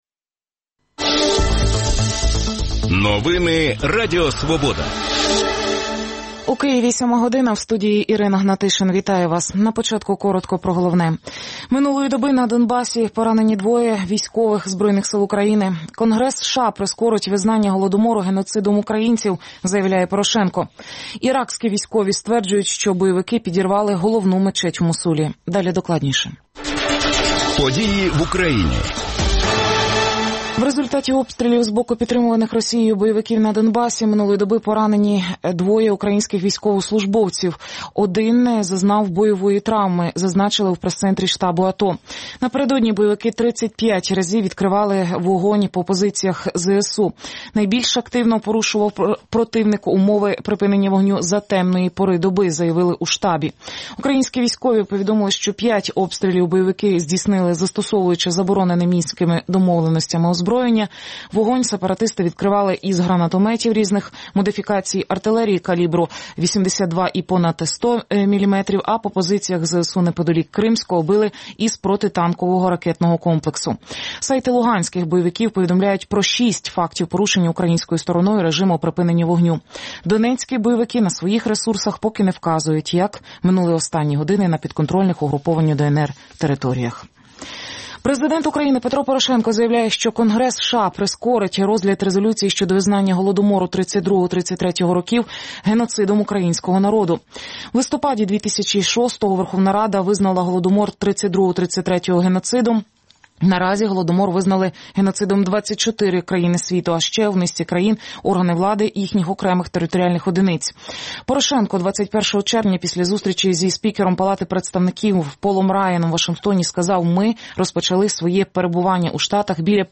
гості студії